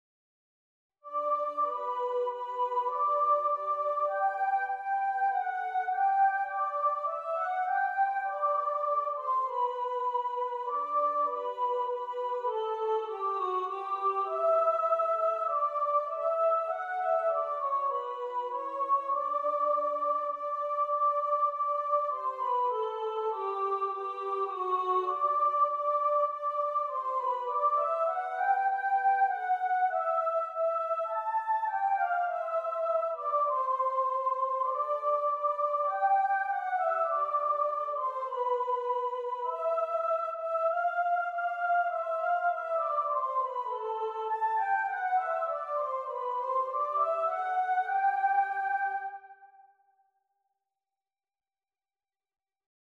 Hark! The Herald Angels Sing – Soprano Descant | Ipswich Hospital Community Choir
Hark-The-Herald-Angels-Sing-Soprano-Descant.mp3